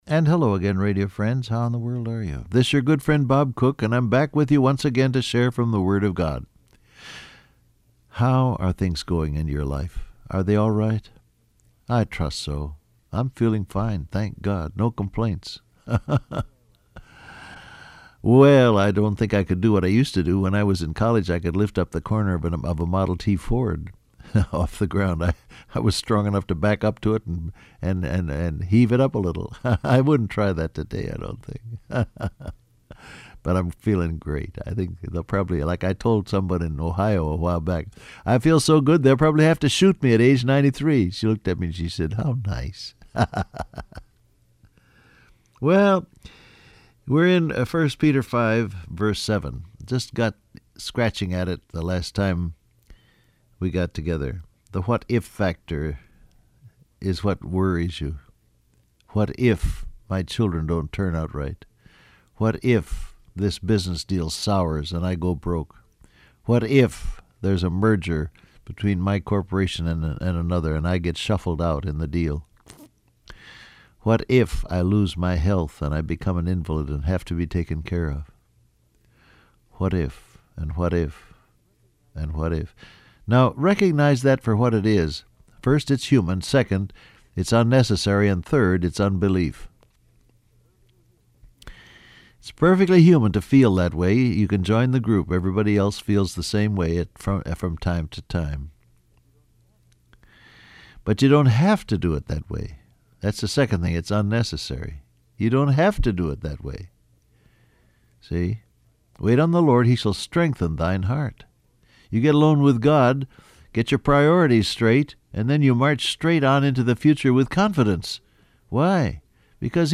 Download Audio Print Broadcast #7186 Scripture: 1 Peter 5:7 , John 14 Topics: God's Will , Worry , God's Care Transcript Facebook Twitter WhatsApp And hello again radio friends.